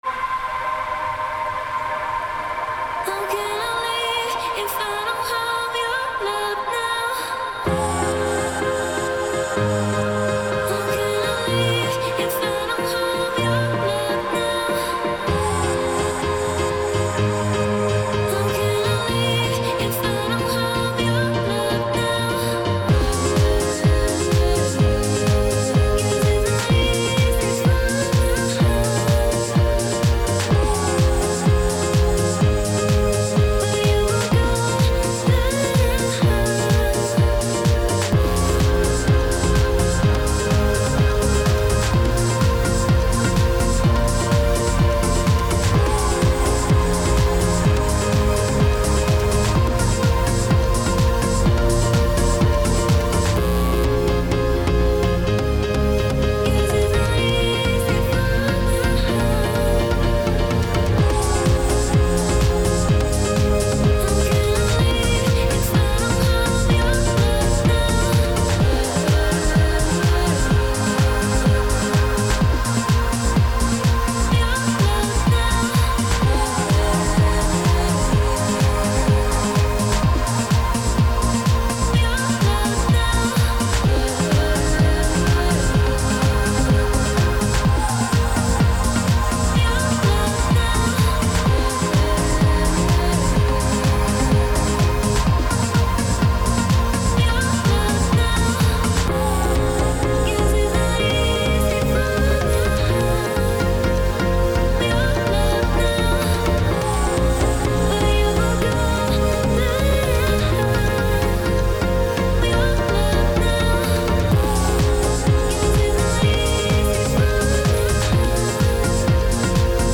Tempo 126BPM (Allegro)
Genre House
Type Vocal Music
Mood energetic
Piano Key F Major
Vocal Keys C, D#, D# Major, G#
Instruments Bass - Buzz (Hard)
Keys - Piano
Percussion - Kick Drum, Clap, Hihats
Plucks - Guitar (chordless), Bass Guitar (bass nullified)
Voice - Adlibs, Textures, Vocals as lyrics